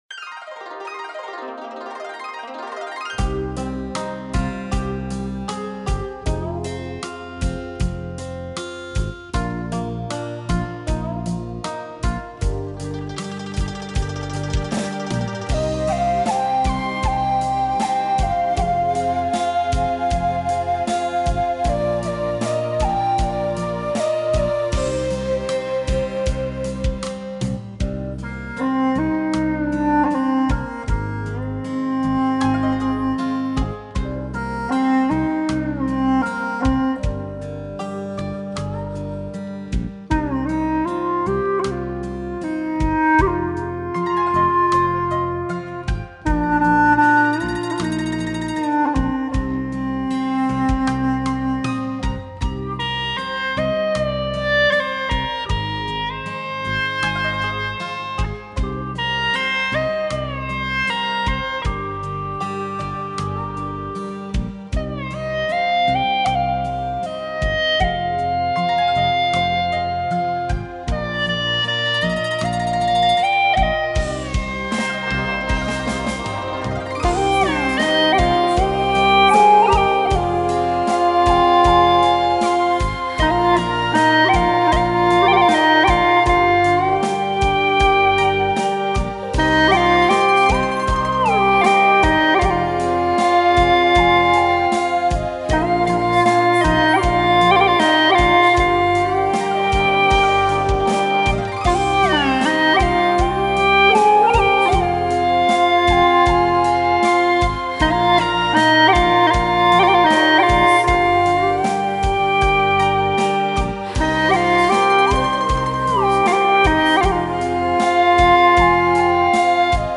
调式 : 降E 曲类 : 流行